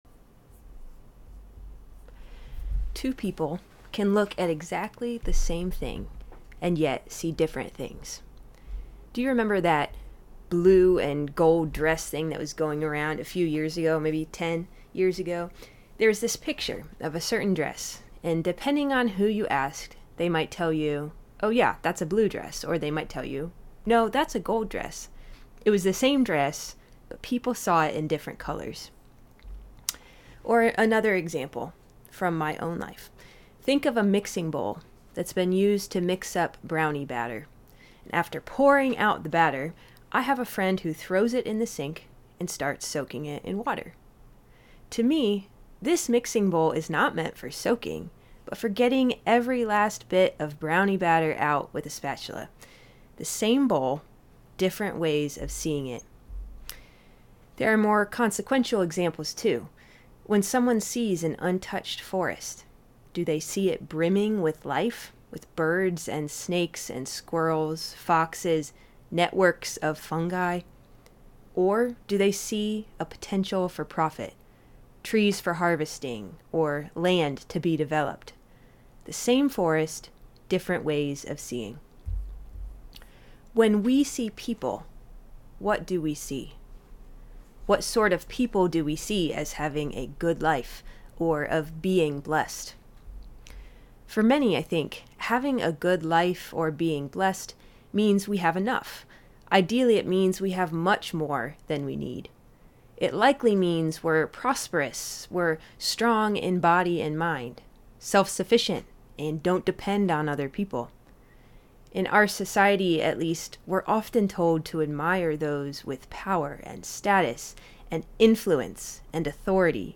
Sermon-2_1_26.m4a